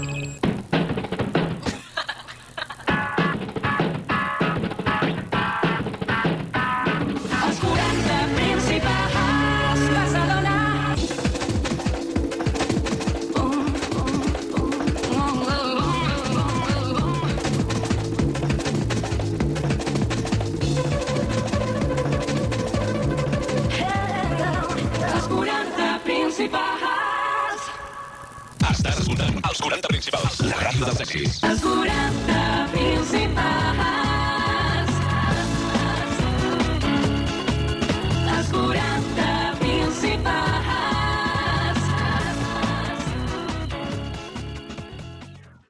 Indicatiu de l'emissora
FM